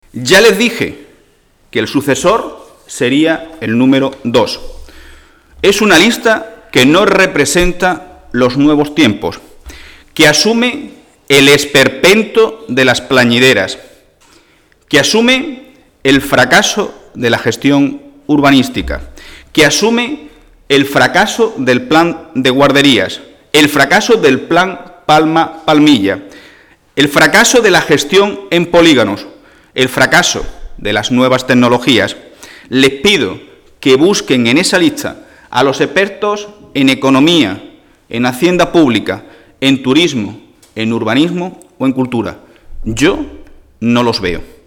El secretario general del PSOE malagueño, Miguel Ángel Heredia, ha asegurado hoy en rueda de prensa, acompañado del diputado socialista Daniel Pérez, que la lista del PP en Málaga capital "no representa los nuevos tiempos".